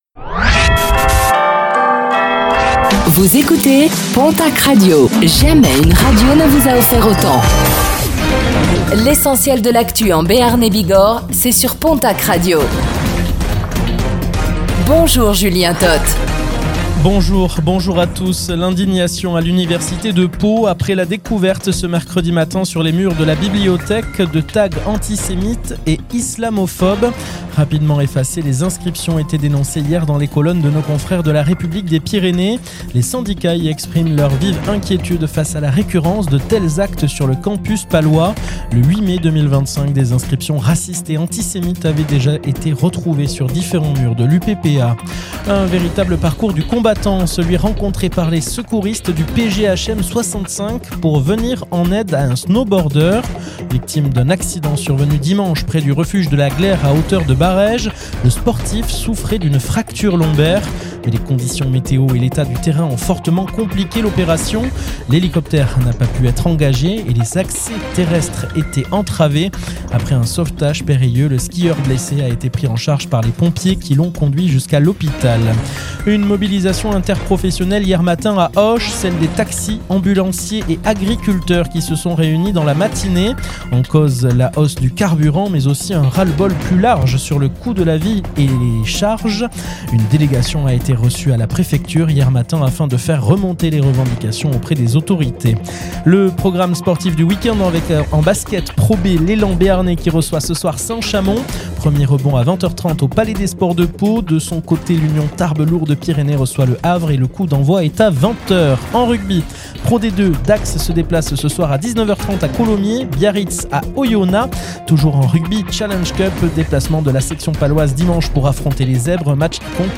Réécoutez le flash d'information locale de ce vendredi 03 avril 2026